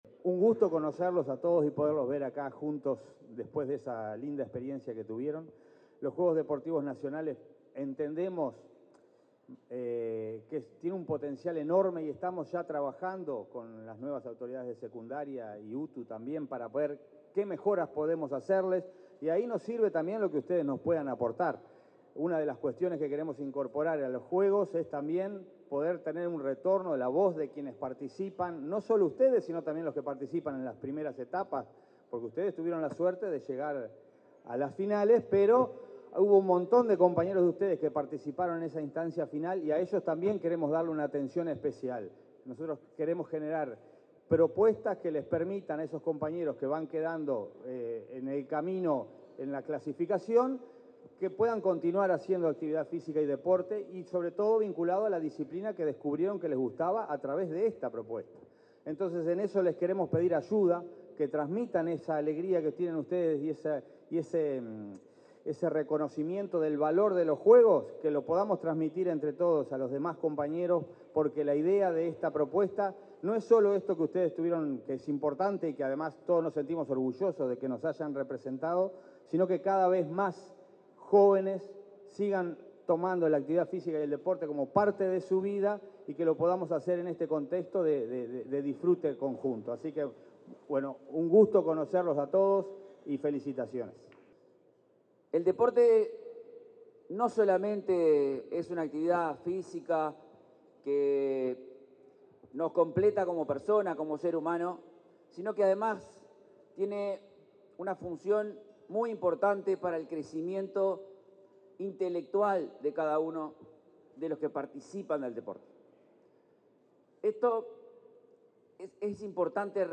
El secretario nacional del Deporte, Alejandro Pereda, y el director general de Educación Secundaria, Manuel Oroño, hicieron uso de la palabra en el acto de reconocimiento a estudiantes de enseñanza media que participaron de los Juegos Sudamericanos Escolares en Colombia en el año 2024.